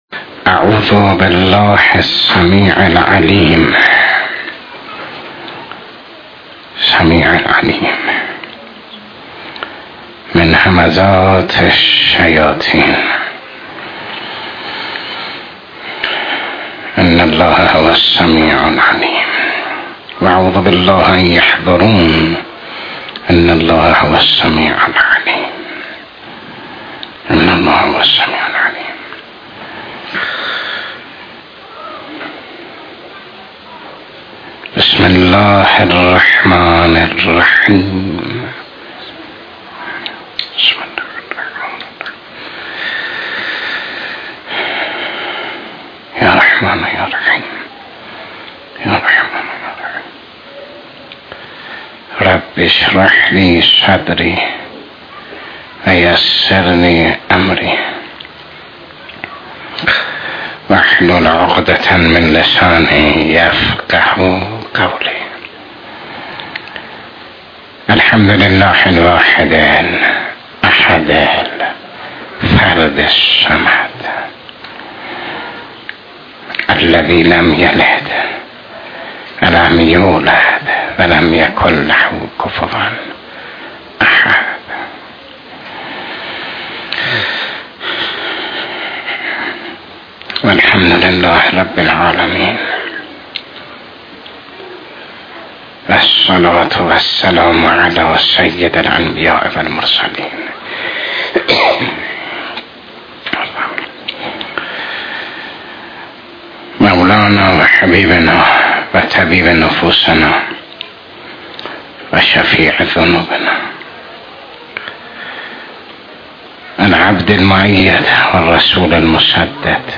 شب گذشته در جلسۀ درس اخلاق